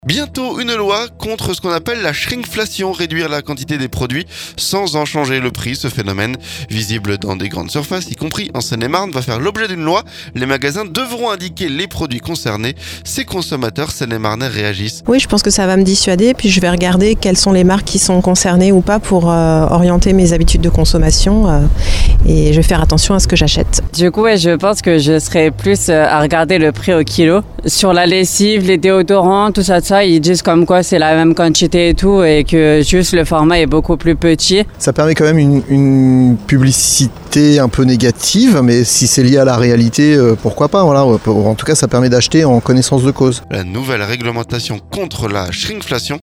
Les magasins devront indiquer les produits concernés dès le 1er juillet. Ces consommateurs seine-et-marnais réagissent.